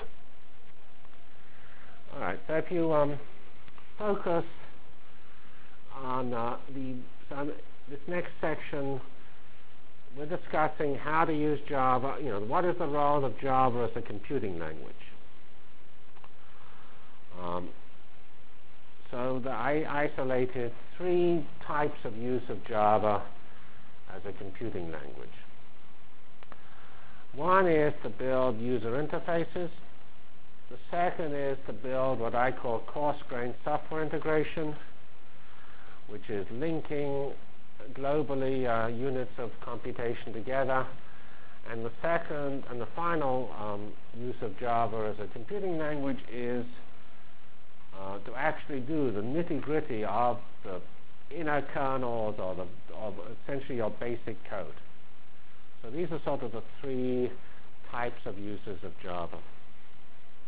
From Feb 5 Delivered Lecture for Course CPS616 -- Java as a Computional Science and Engineering Programming Language CPS616 spring 1997 -- Feb 5 1997.